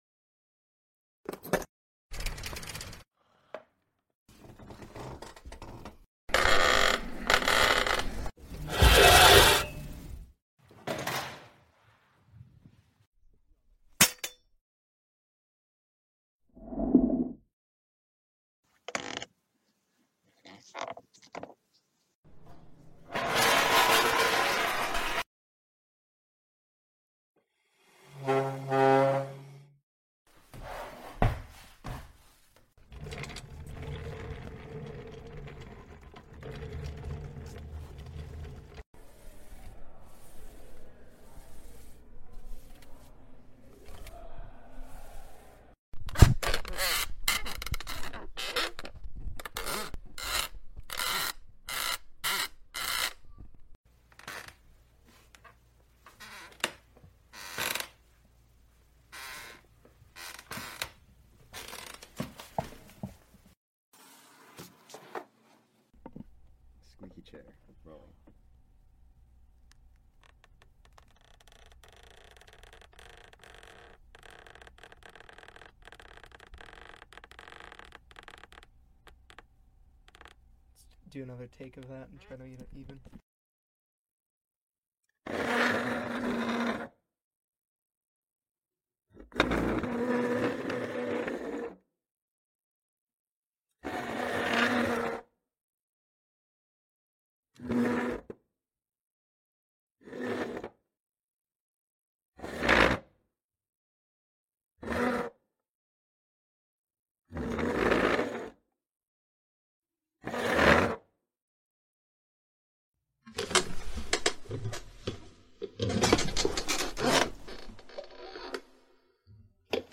Tổng hợp hiệu ứng âm thanh tiếng Ghế kêu
Tiếng Ngồi Xuống Ghế văn phòng Tiếng Ghế Kêu cót két, kọt kẹt…
Thể loại: Tiếng đồ vật
Description: Tổng hợp hiệu ứng âm thanh ghế nhựa, ghế va chạm, ghế trẻ em chạm sàn nhanh hơn, ghế, ghế di chuyển, ghế kim loại, ghế cót két, ghế cót két, ghế kêu cót két, ghế kêu cót két như chim, ghế cót két, ghế cót két, ghế tự động hạ xuống, ghế cào, ghế cuộn, ghế cót két, ghế cót két - nhiều tiếng cót két, ghế trượt chậm, ghế kim loại mở ra, ghế văn phòng điều chỉnh, ghế di chuyển trên đá cẩm thạch, đẩy ghế, ghế trượt trên thảm, ghế cũ cót két, ghế cót két nghiêng dài, ghế lăn, ghế kim loại gấp lại bị rơi....
tong-hop-hieu-ung-am-thanh-tieng-ghe-keu-www_tiengdong_com.mp3